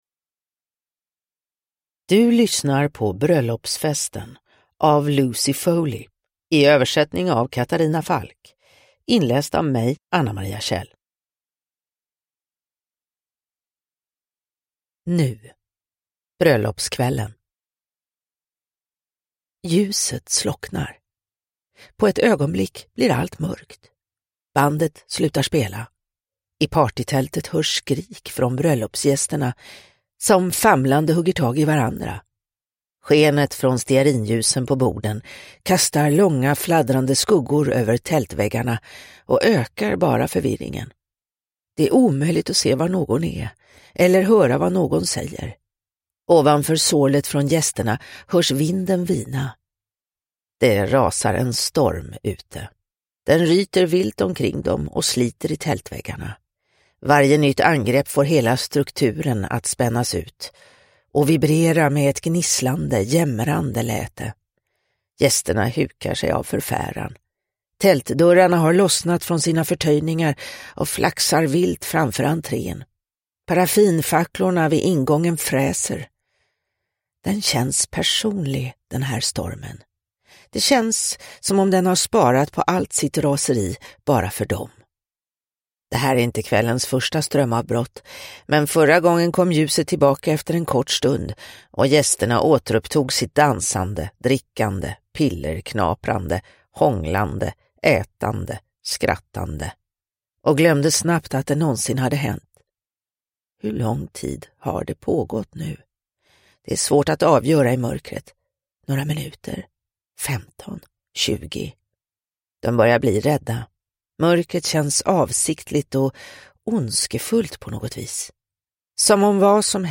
Bröllopsfesten – Ljudbok – Laddas ner